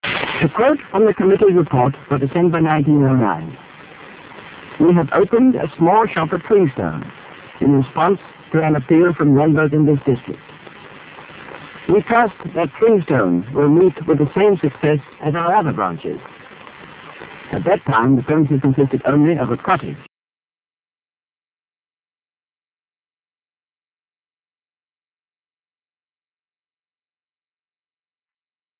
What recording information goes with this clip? In order to keep the file size minimal, there has been an inevitable reduction in clarity,